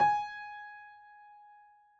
piano_068.wav